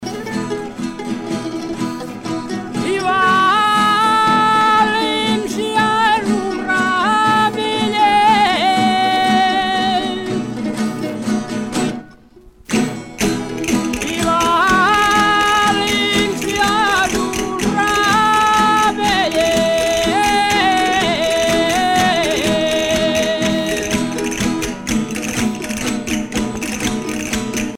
danse : jota (Espagne)